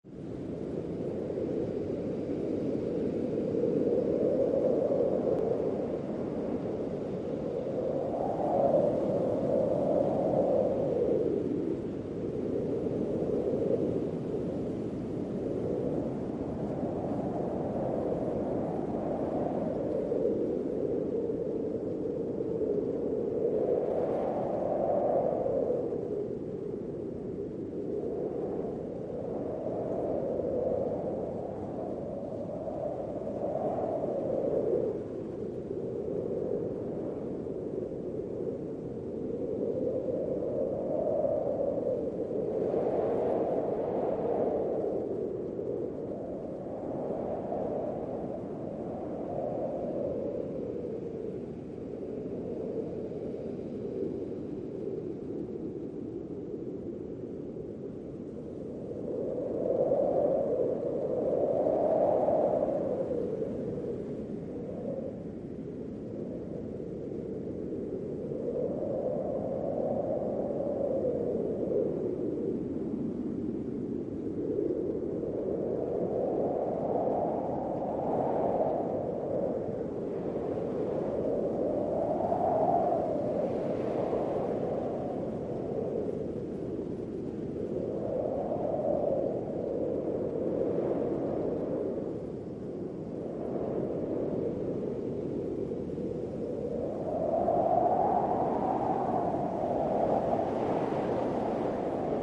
wind.mp3